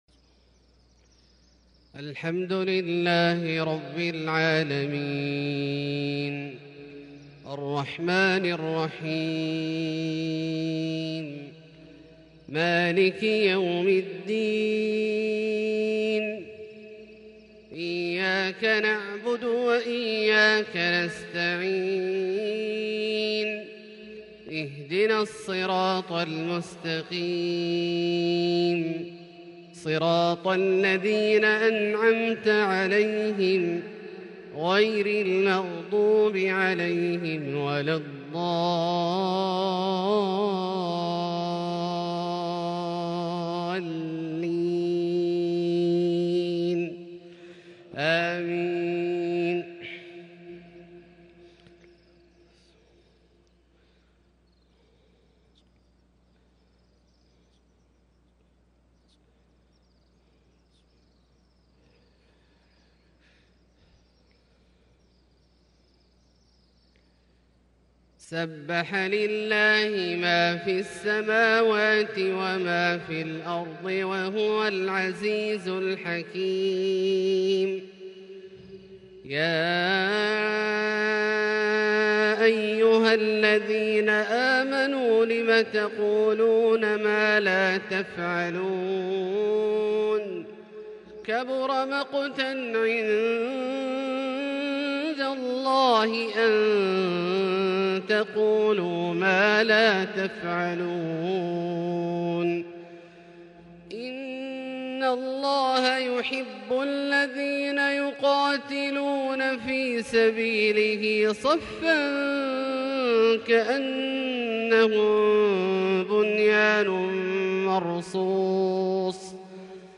فجر الثلاثاء 6 شوال 1442هـ سورة الصف > ١٤٤٢ هـ > الفروض - تلاوات عبدالله الجهني